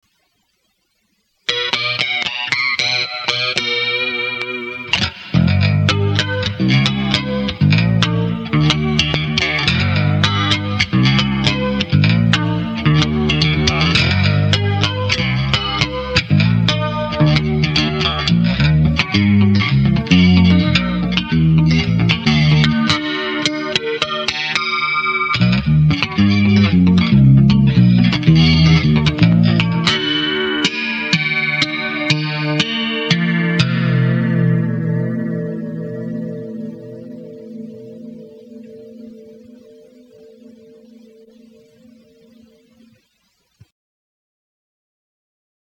NOTE: bass solo